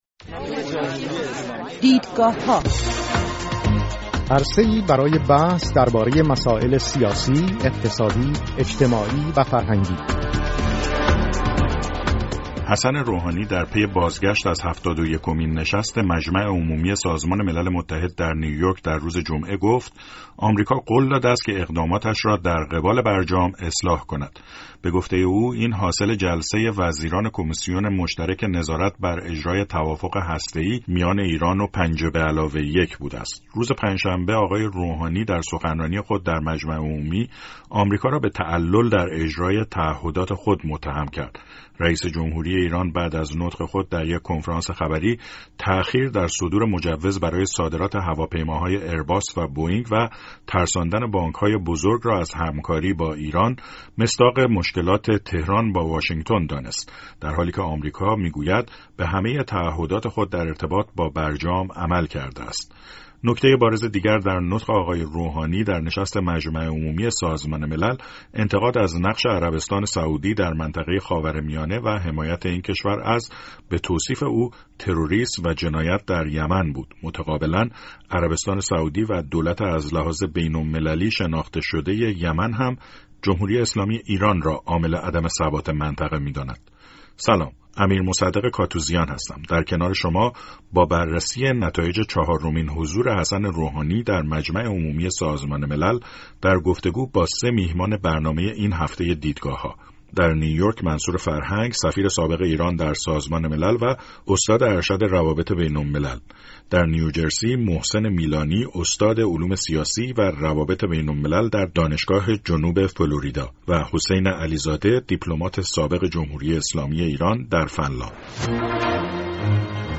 حسن روحانی رئیس‌جمهور ایران، امسال برای چهارمین سال پیاپی در نشست مجمع عمومی سازمان ملل حضور یافت. سه میهمان برنامه این هفته «دیدگاه‌ها» نتایج چهارمین حضور او در مجمع عمومی را بررسی می‌کنند.